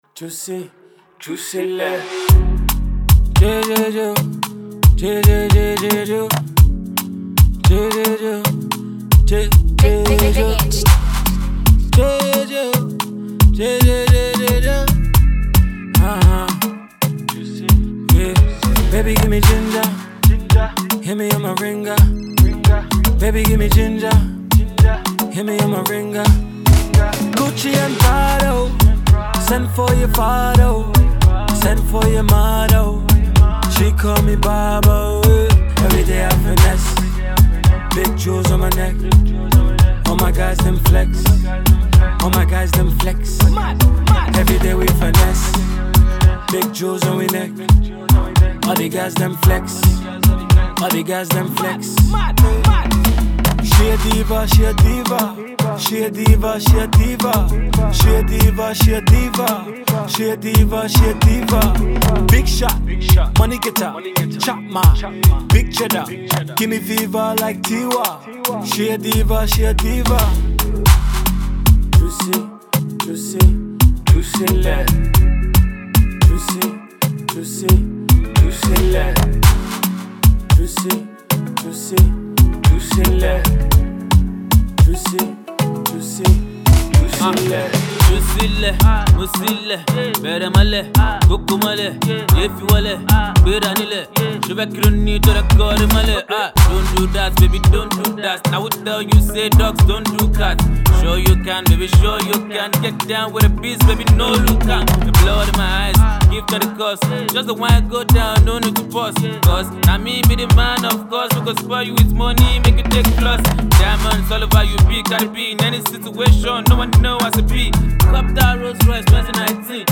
Acclaimed Afrobeats artist